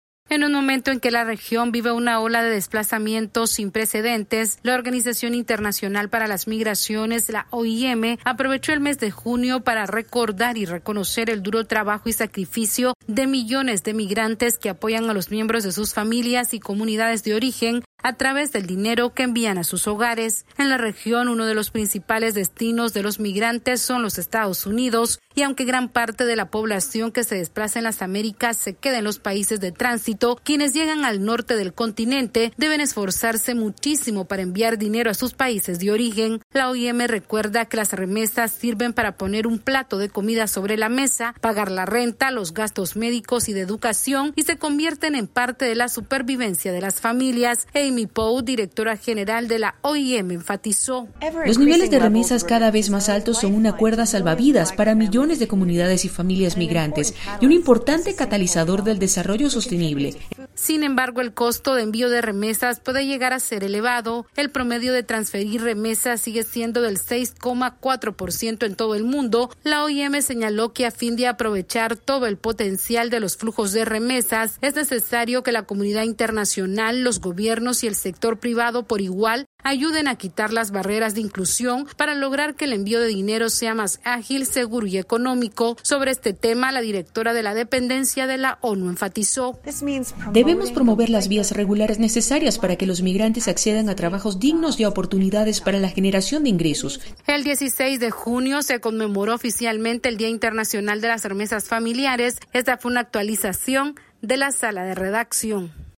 La Organización Internacional para las Migraciones destacó la importancia de las remesas familiares para la sostenibilidad y desarrollo social de las familias de los migrantes en sus países de origen. Esta es una actualización de nuestra Sala de Redacción...